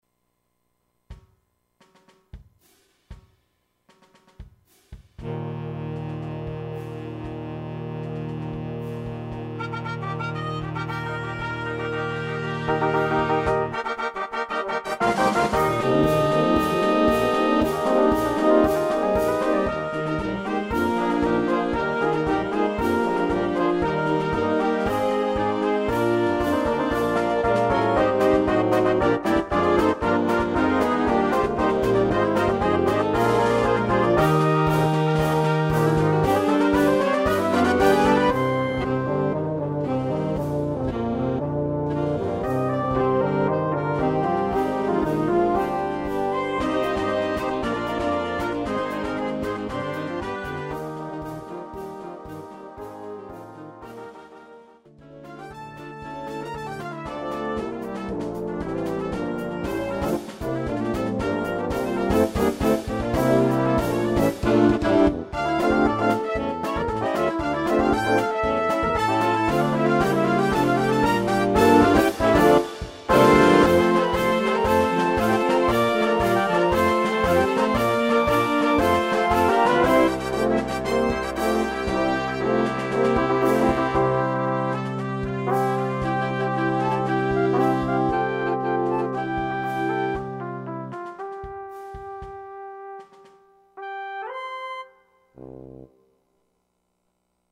Blaasorkest
Demo